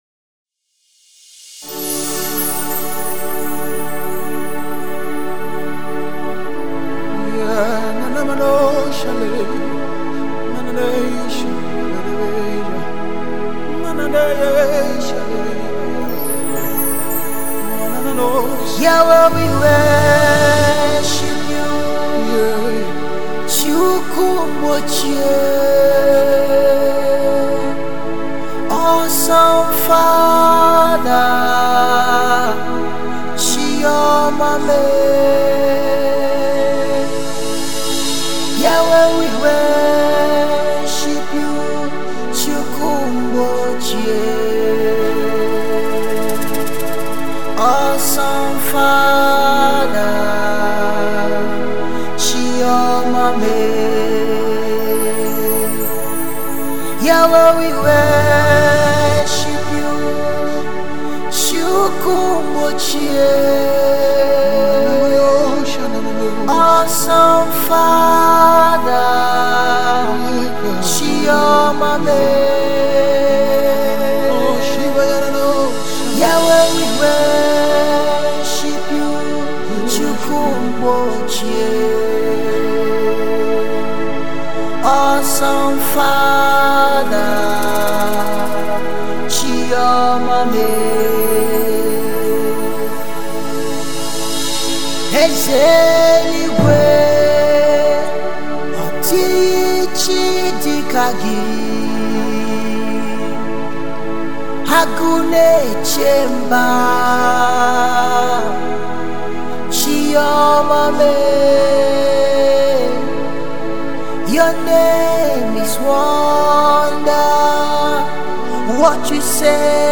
This song will usher you in the mode of worship and prayer.